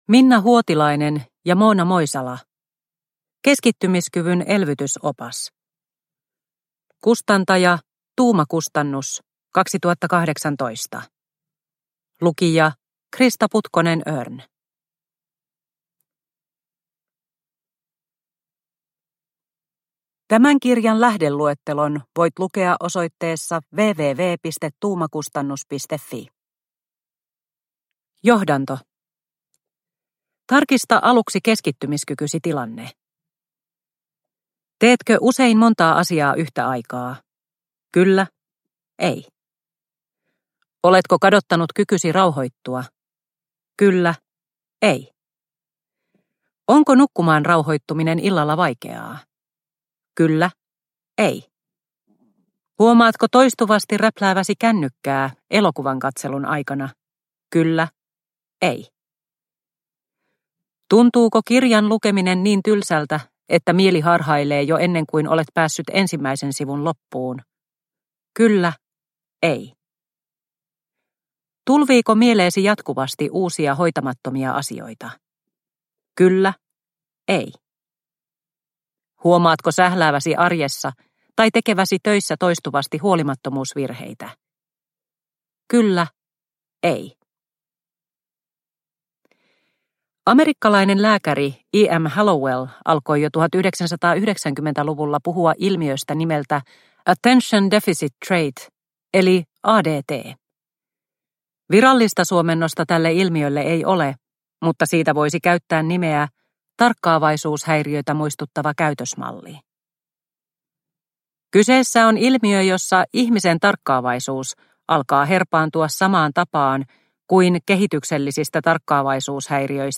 Keskittymiskyvyn elvytysopas – Ljudbok – Laddas ner